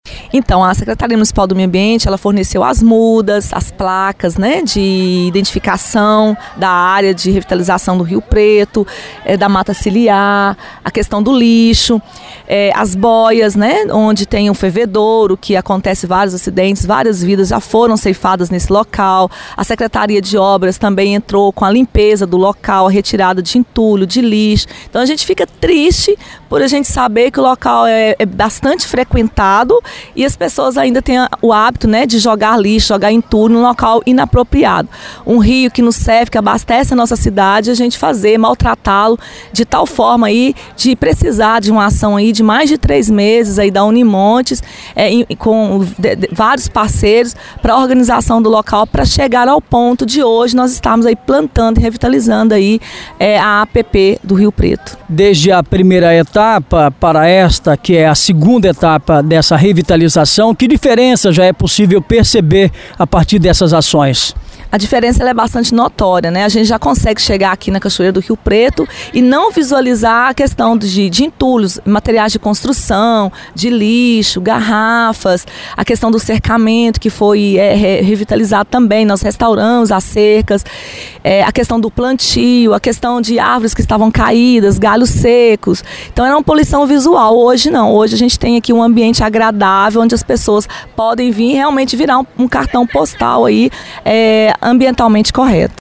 Os trabalhos estão sendo acompanhados de perto pela Secretária Municipal de Meio Ambiente, Cátia Regina, que explicou como tem sido a participação da prefeitura nas ações do projeto.